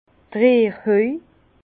vin_67_p11_rierhoj_0.mp3 Catégorie Les outils du vin Page page 11 Bas Rhin d' Rierhöj Haut Rhin / Français houe Ville Bas-Rhin Strasbourg Ville Haut-Rhin Mulhouse Ville Prononciation 67 Herrlisheim Ville Prononciation 68 Munster